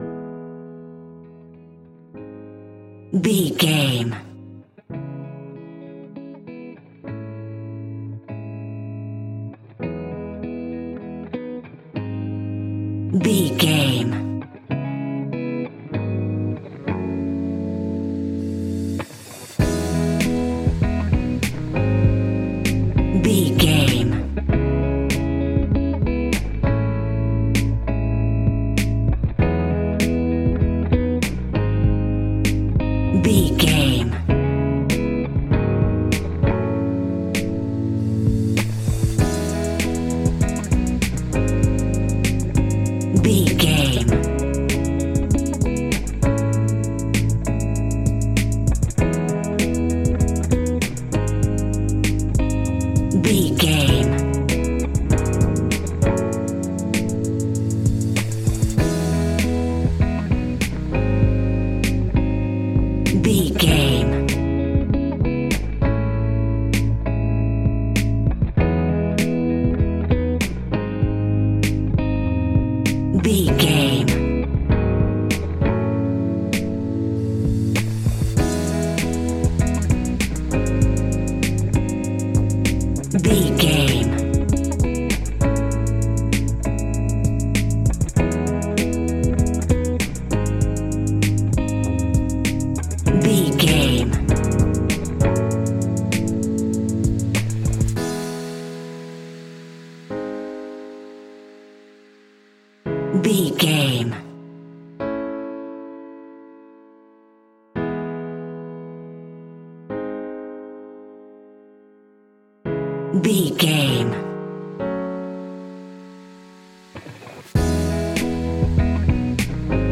Ionian/Major
chilled
laid back
groove
hip hop drums
hip hop synths
piano
hip hop pads